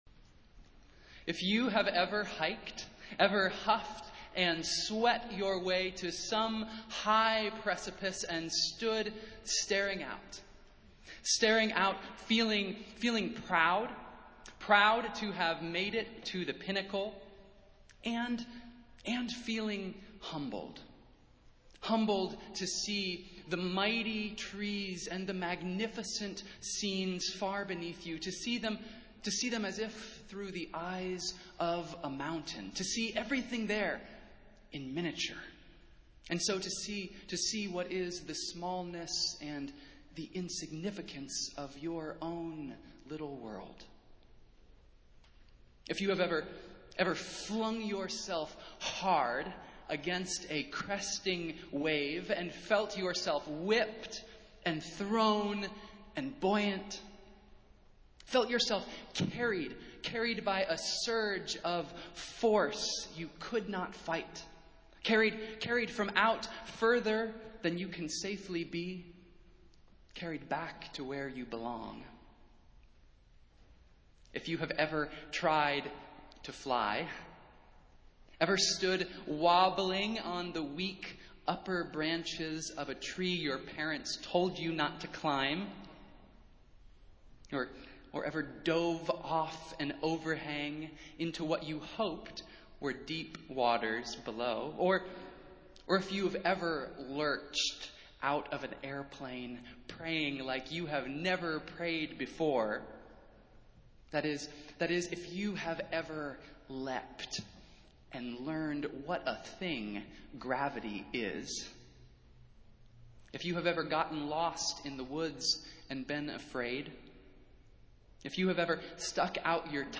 Festival Worship - Earth Sunday | Old South Church in Boston, MA
Festival Worship - Earth Sunday